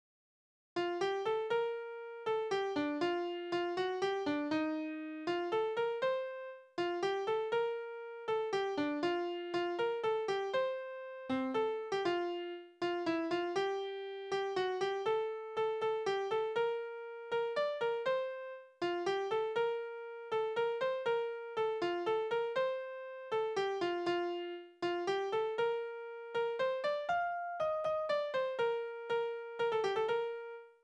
Tonart: B-Dur
Taktart: 3/4
Tonumfang: kleine Dezime
Besetzung: vokal